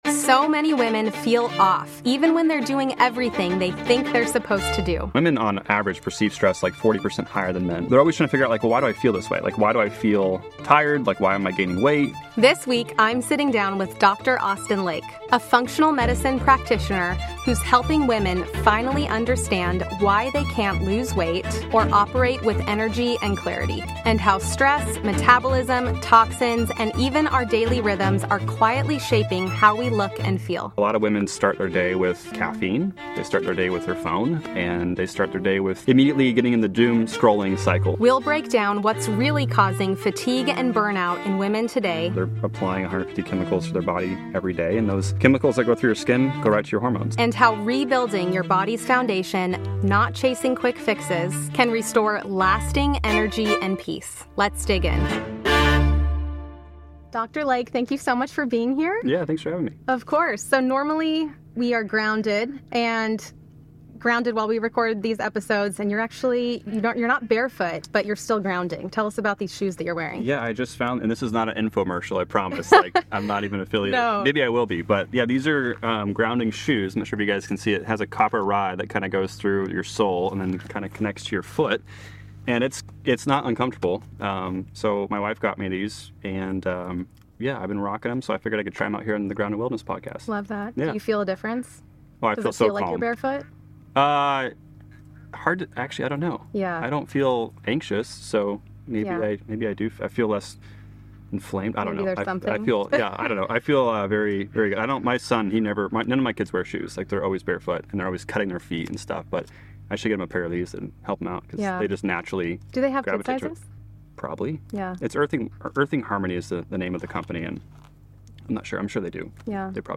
What starts as a conversation about circadian rhythm becomes a bigger invitation: to rebuild the body’s foundation through consistent sleep, morning light, steady nourishment, and a reduced toxic load - not as another “protocol,” but as a way to restore calm, clarity, and resilience from the inside out.